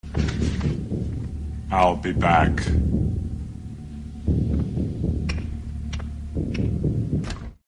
Arnold Schwarzenegger as Terminator in "Terminator" film -- "I'll be back."